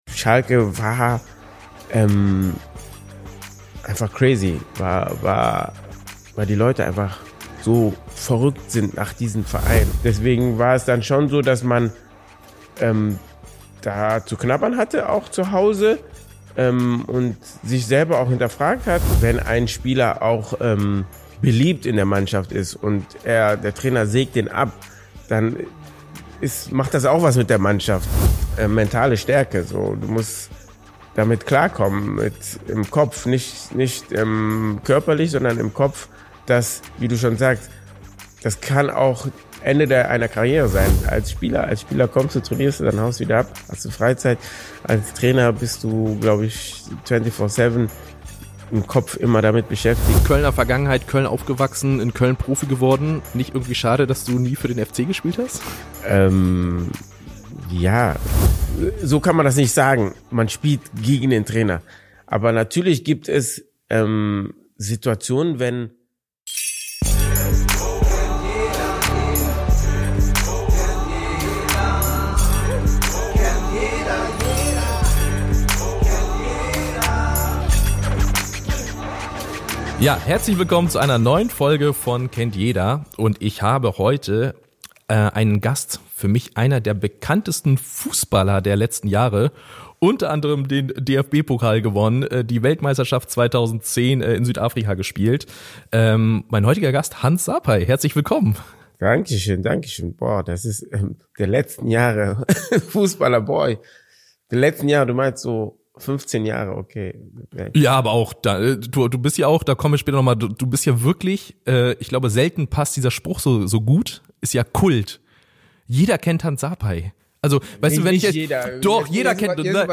In der neuen Folge von „Kennt jeder“ ist Hans Sarpei zu Gast – Ex-Profi, Nationalspieler und einer der ersten echten Fußball-Influencer Deutschlands.
Hans erzählt ehrlich und mit viel Witz über seine Zeit in der Bundesliga, seine größten Herausforderungen und was er aus all den Jahren im Profifußball mitgenommen hat. Natürlich geht’s auch um seine Nationalmannschaftskarriere und das legendäre WM-Achtelfinale 2010 gegen Uruguay – ein Spiel, das bis heute unvergessen ist.